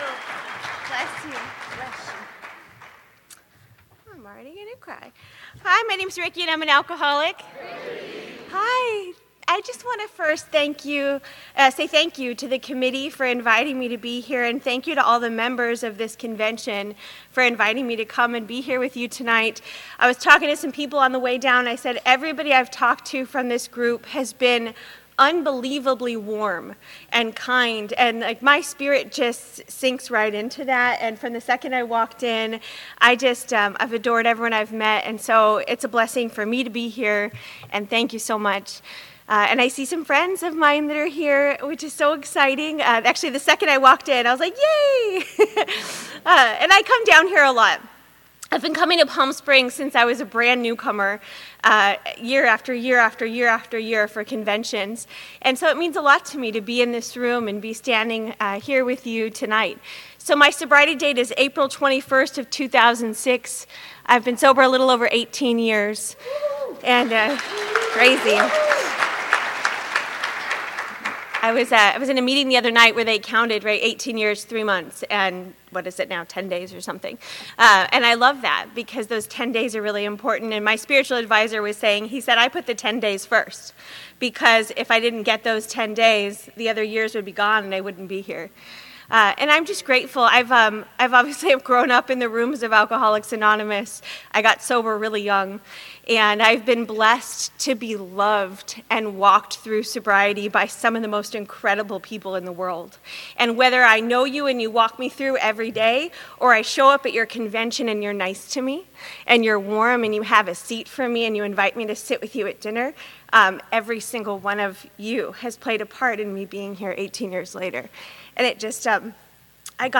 AA - Studio City - 50th MAAD DOG DAZE 2024